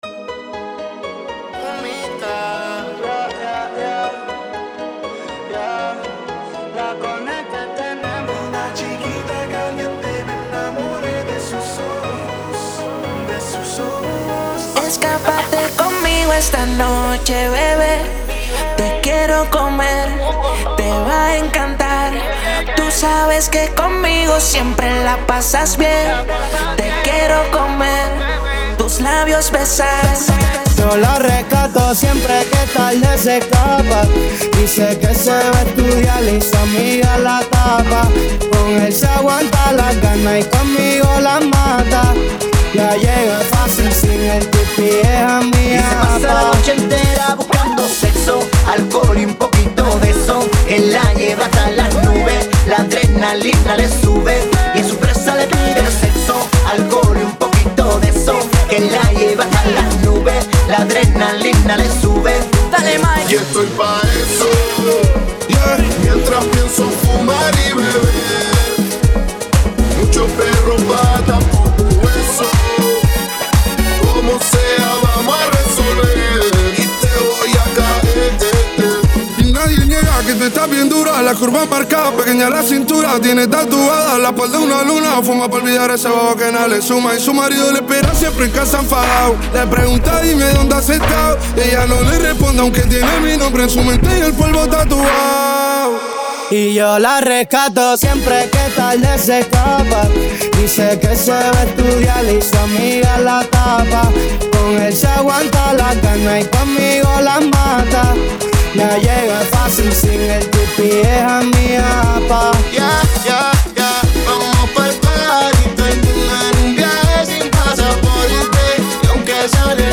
Acapella
Instrumental